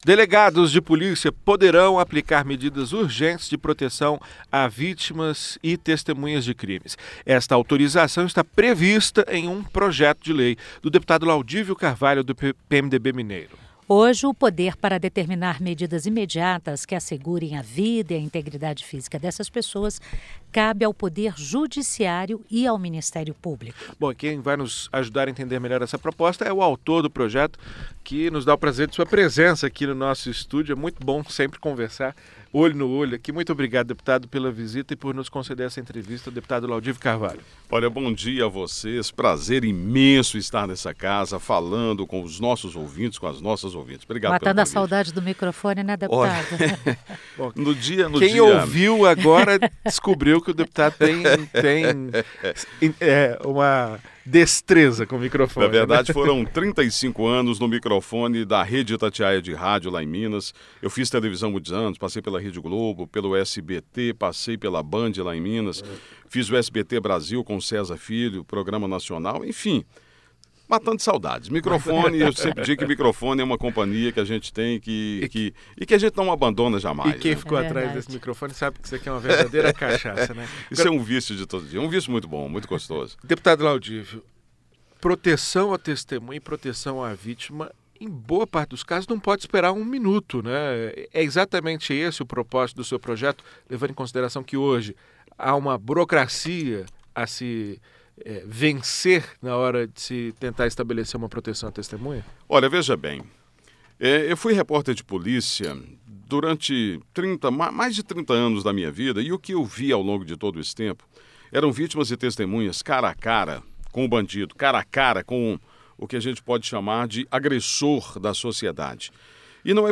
Em entrevista ao Com a Palavra… o deputado Laudívio Carvalho (PMDB-MG) apresentou detalhes do projeto. Ele acredita que, se aprovado, não haverá interferência da polícia no Judiciário e que a proposta reduzirá a burocracia e facilitará a proteção às vítimas e testemunhas.